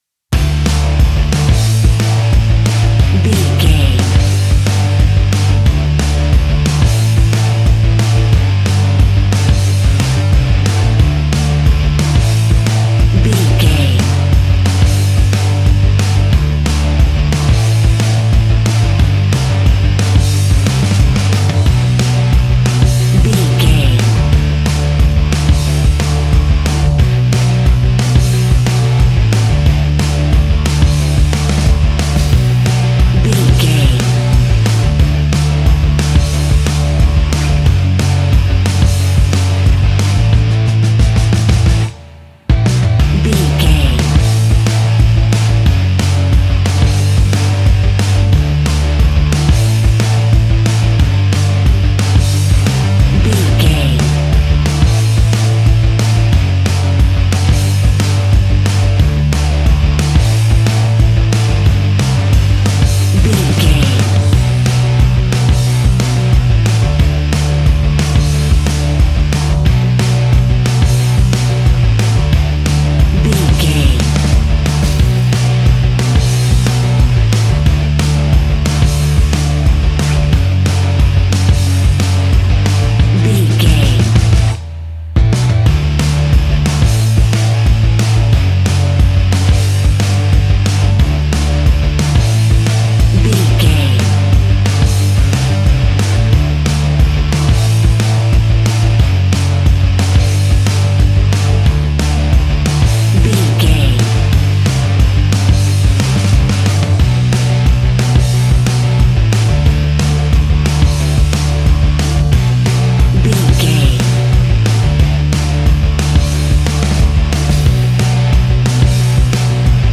Epic / Action
Fast paced
Ionian/Major
D
hard rock
distortion
punk metal
rock guitars
Rock Bass
Rock Drums
distorted guitars
hammond organ